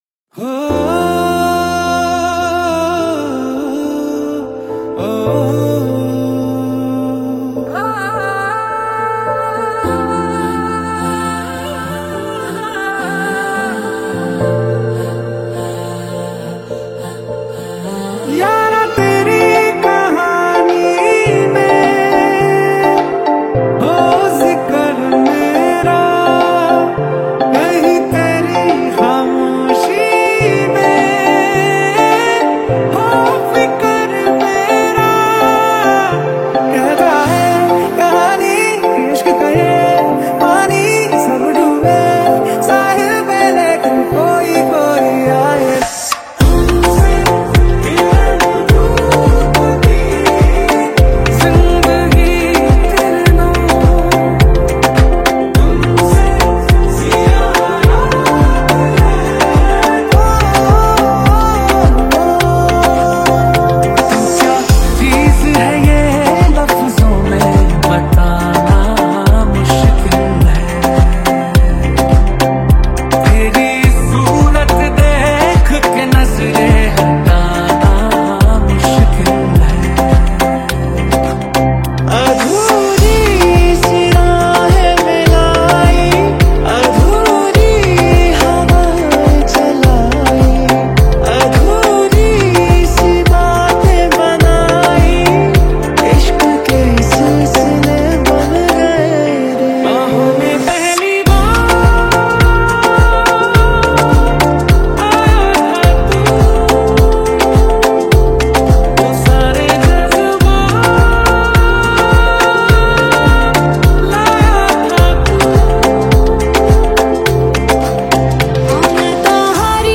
Dj Remix